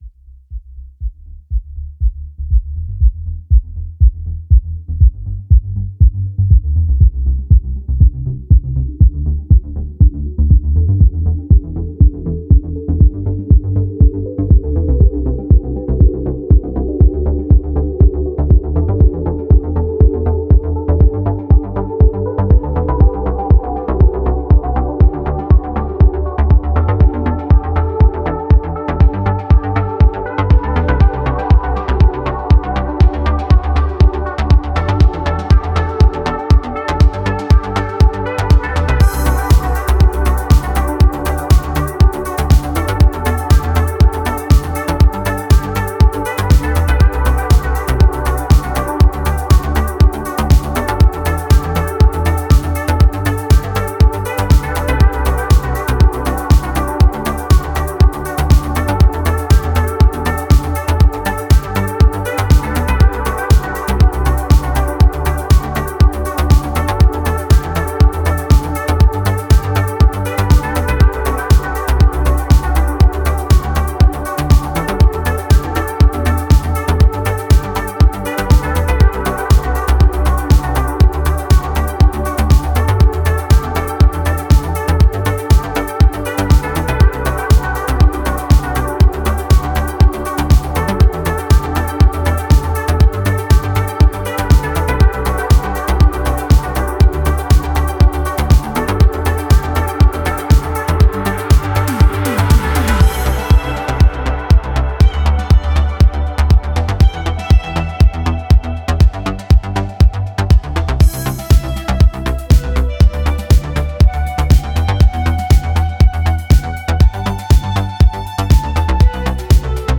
Genre: Dreamwave, Synthwave, Retro Wave.